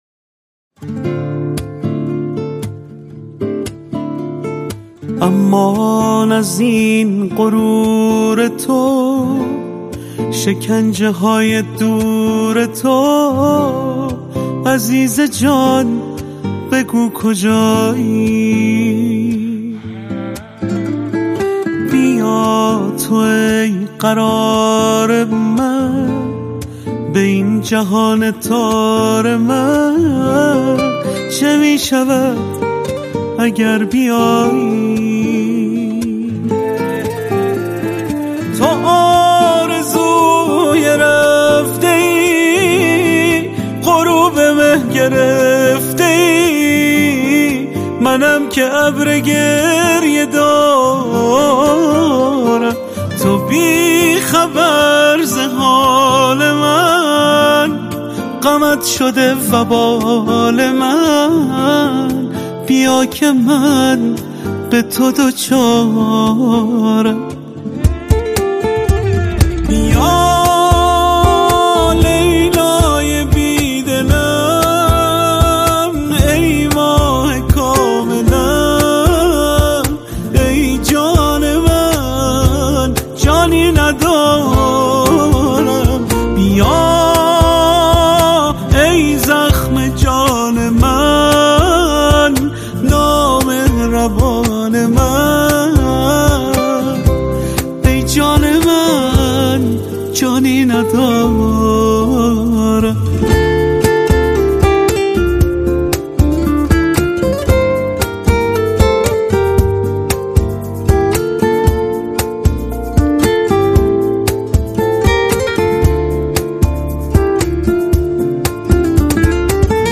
آهنگی سوزناک و پر از غم
آهنگ ایرانی